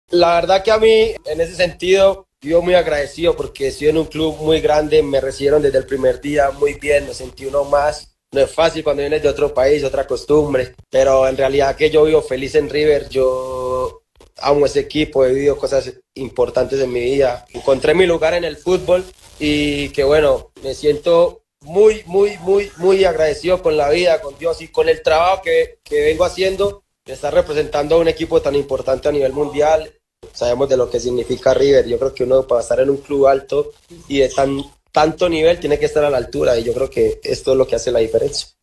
(Juan Fernando Quintero en Instagram Live)